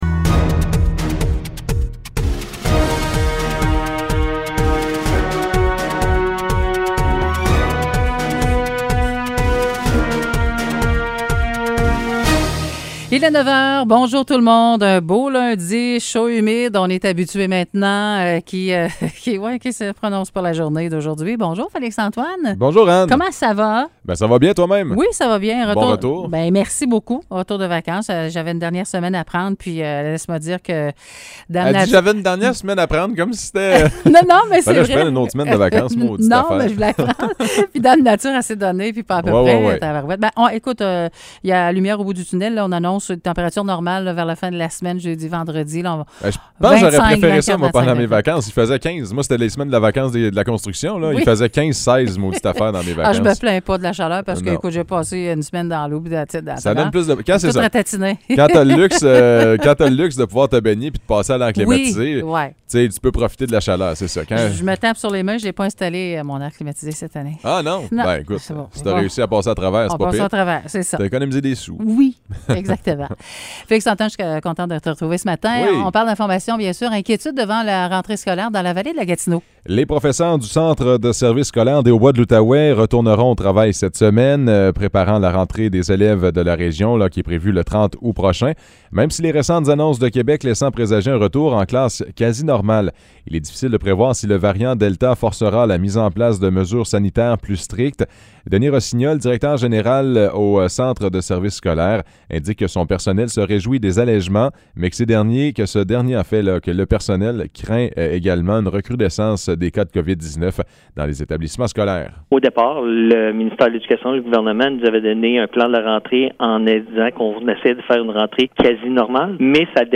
Nouvelles locales - 23 août 2021 - 9 h